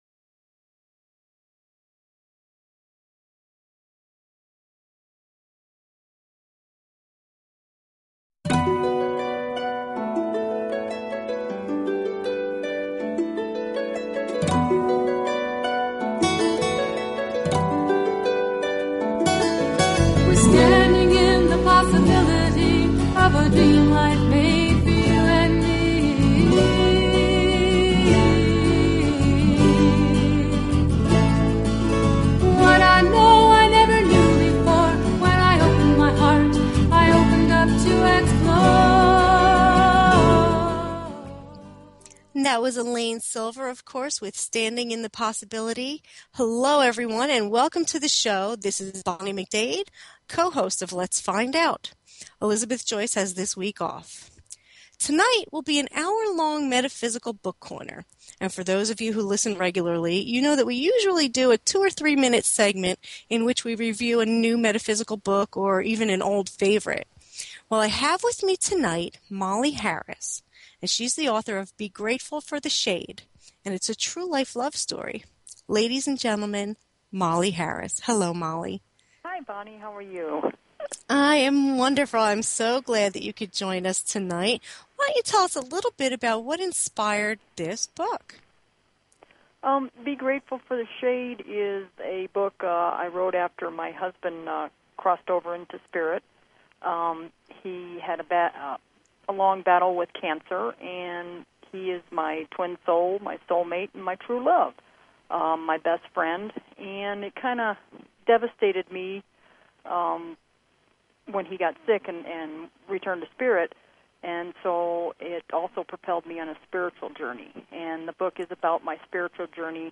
Talk Show Episode
The listener can call in to ask a question on the air.